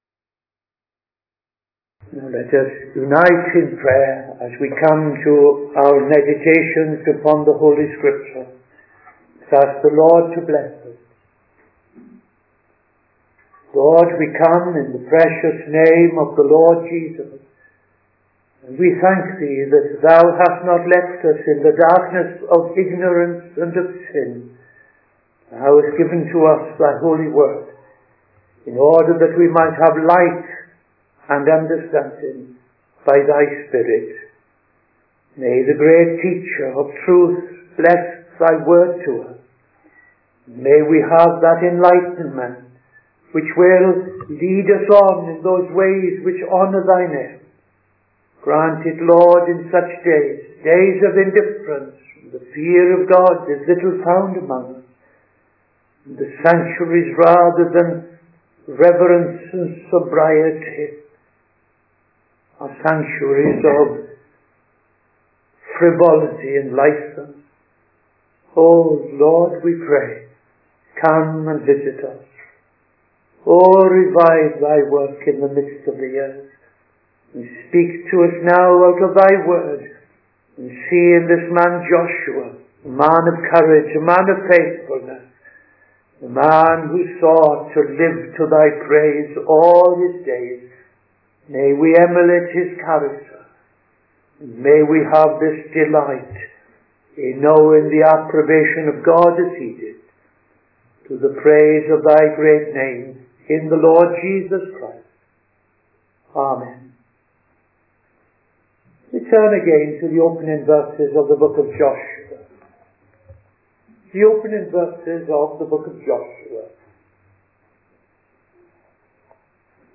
Midday Sermon 14th September 2025